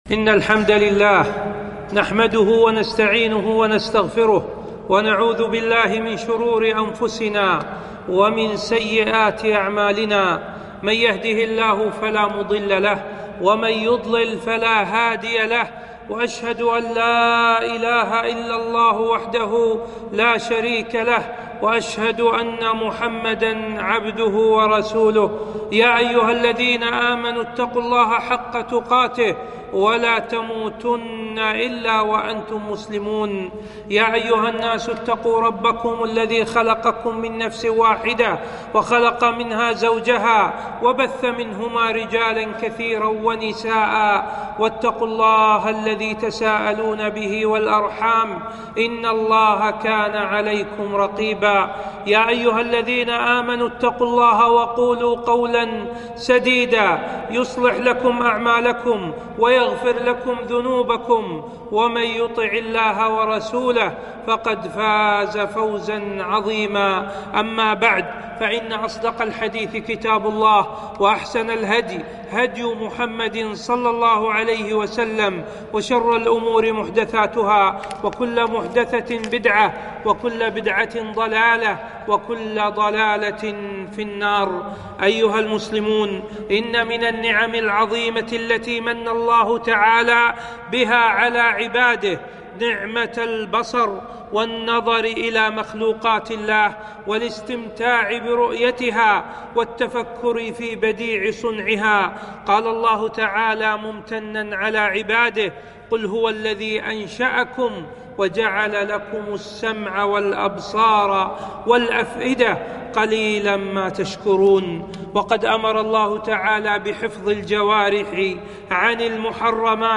خطبة - غض البصر عن الحرام